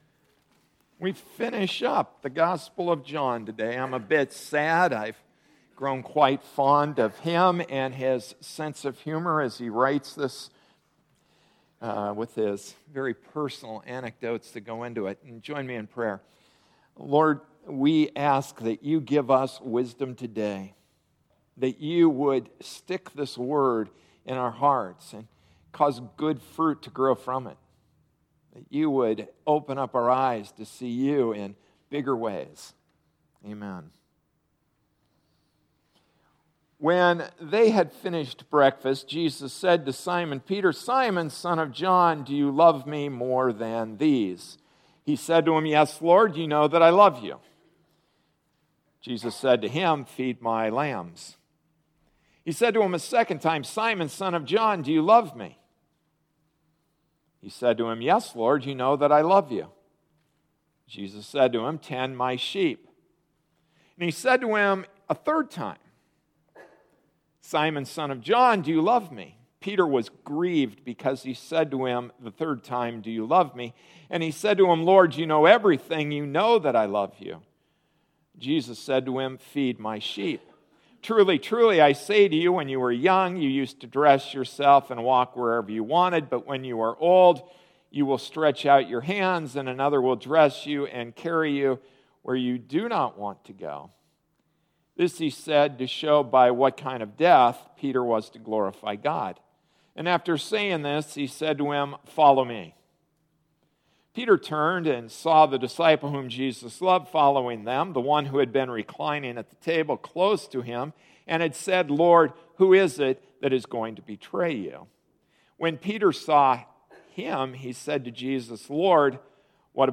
Passage: John 21:15-23 Service Type: Sunday Morning Service “What About Him?”